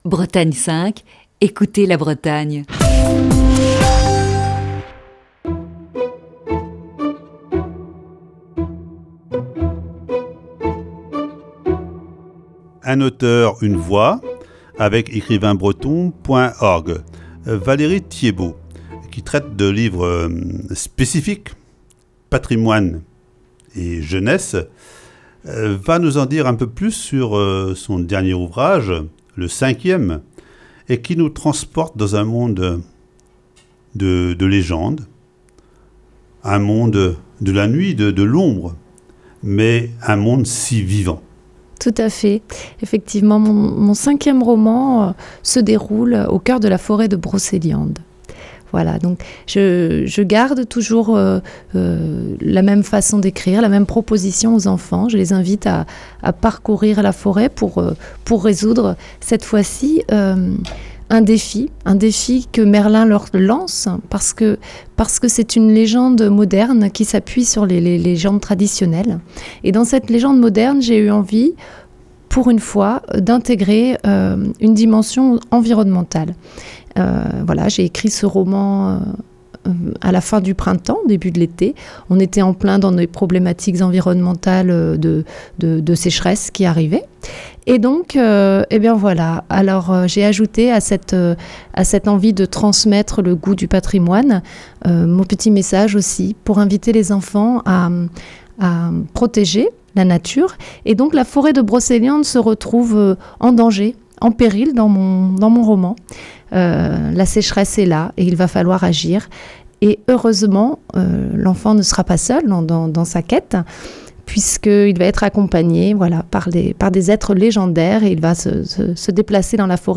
Il nous propose d'écouter ce vendredi la cinquième et dernière partie de cette série d'entretiens.